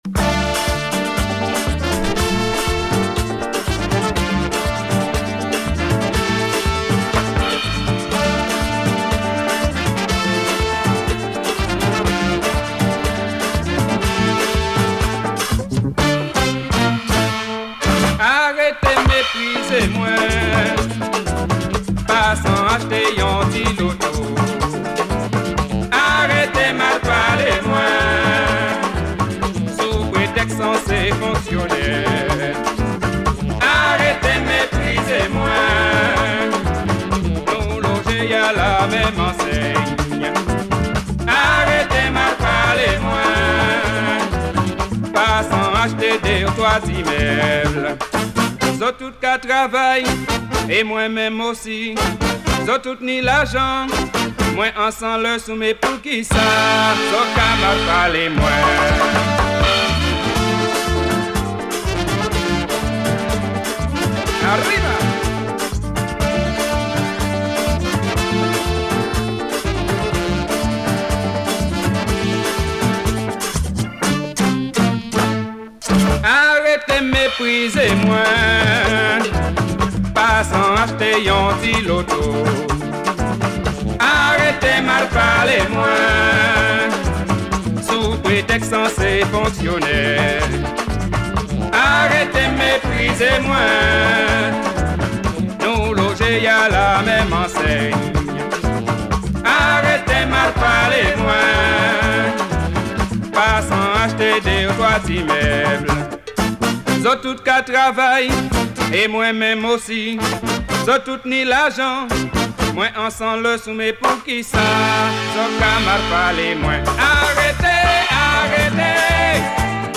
Caribbean